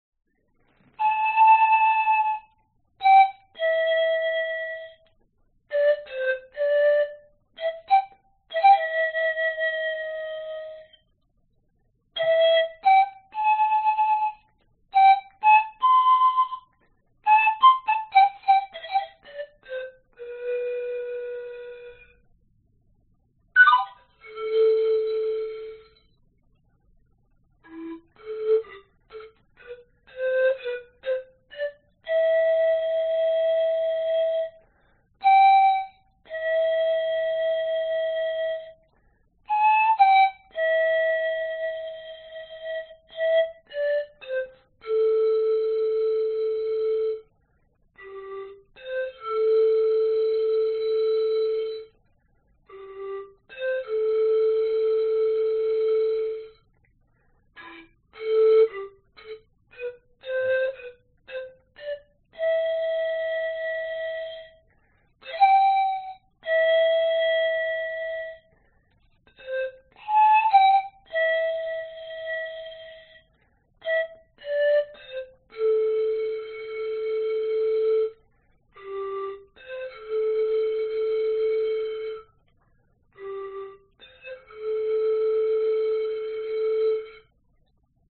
Tag: 长笛 板笛 传统